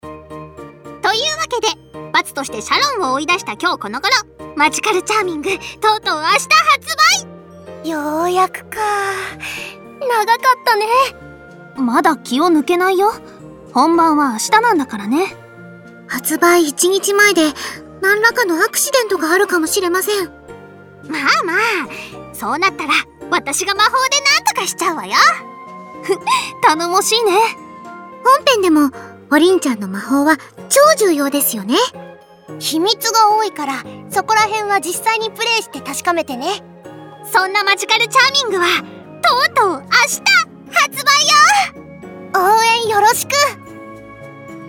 発売一日前カウントダウンボイス公開！